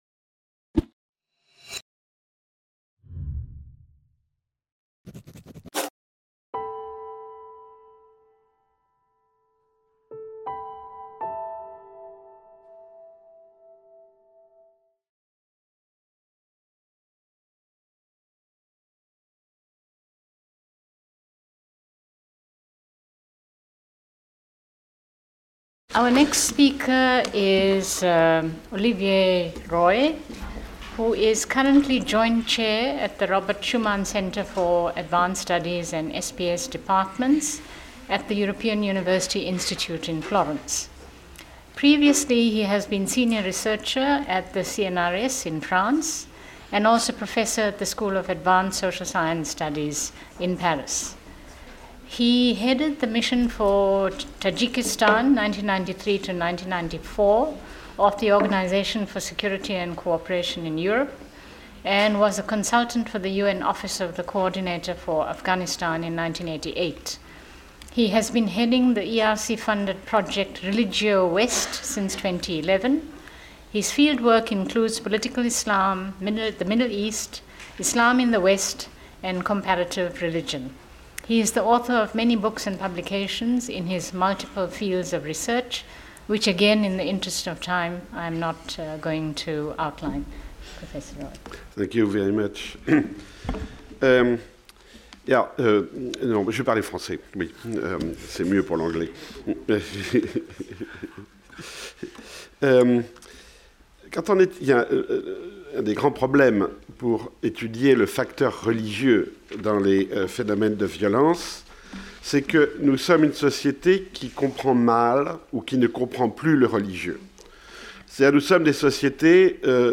Auditorium de l’INALCO 65, rue des Grands Moulins | 75013 Paris